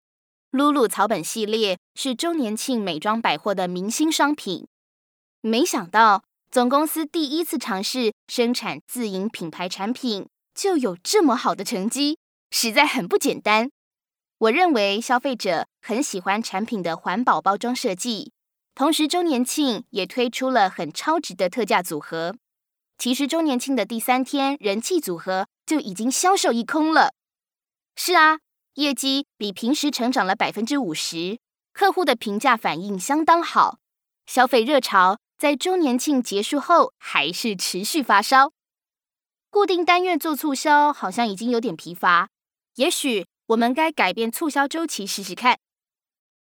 Traditional (Tayvan) Seslendirme
Kadın Ses